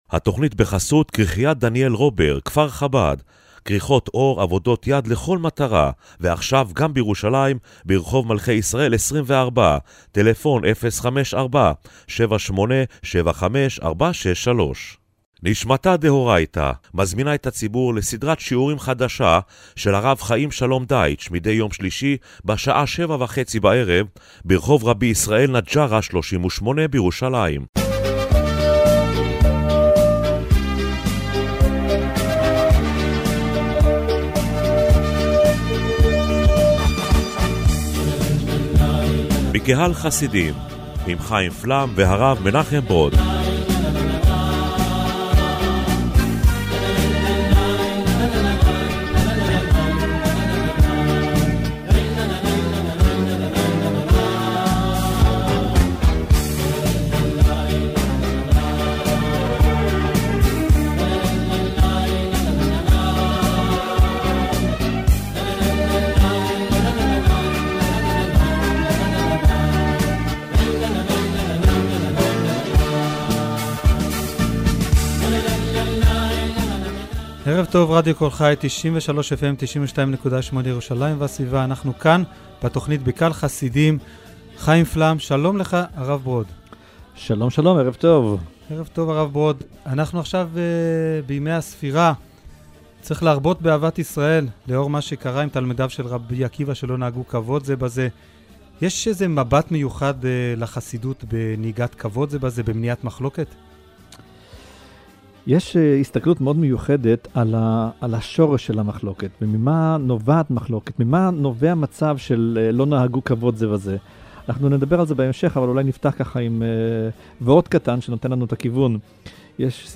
במרכז תכנית הרדיו השבועית בקהל חסידים השבוע עמדה סוגיית מניעת מחלוקת ותיקון העניין שלא נהגו כבוד זה בזה.